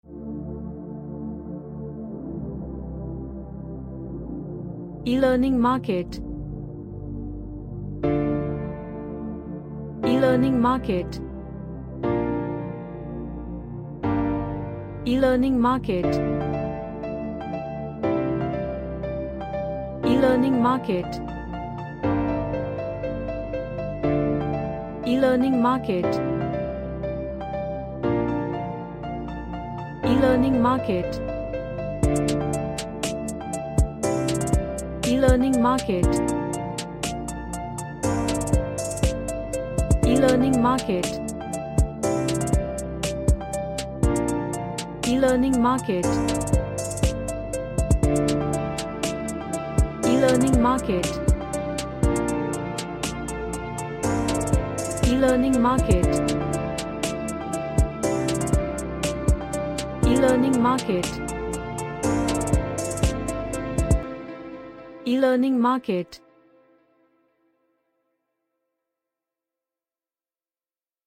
A trap melodical track.
Happy